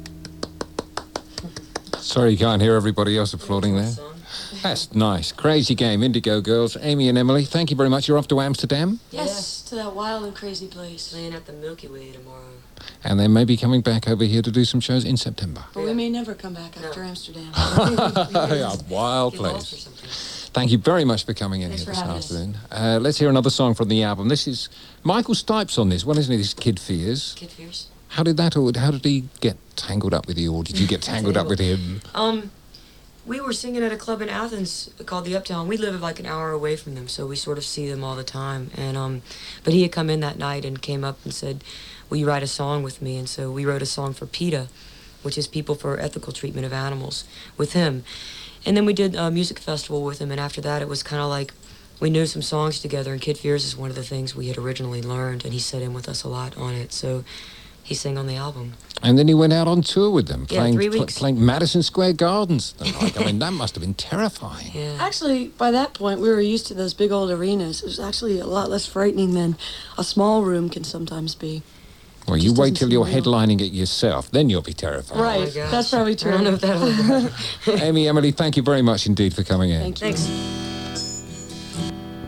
lifeblood: bootlegs: 1989-07-01: radio one - london, england
09. interview (1:22)